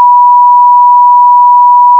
You can listen here to different signals with the same frequency (1KHz) but with different periodic waveform shapes.
Sinusoid
sinu.wav